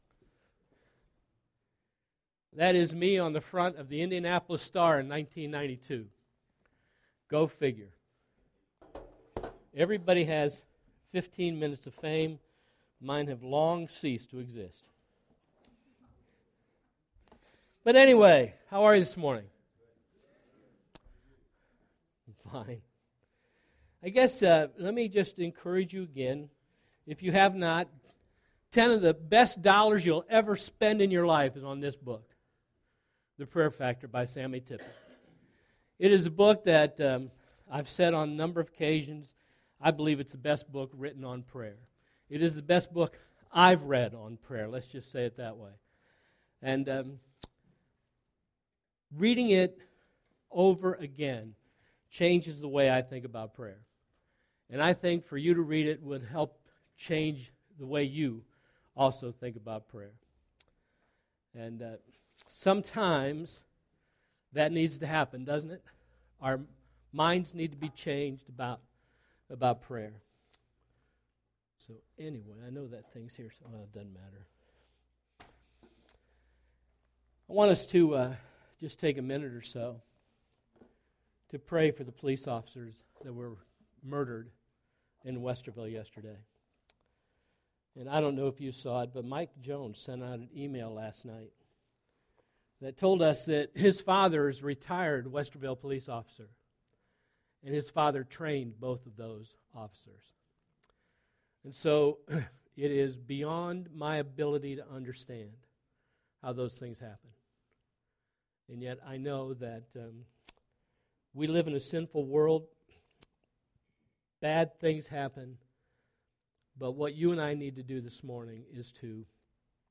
First Baptist Church of Gahanna, OH Sermons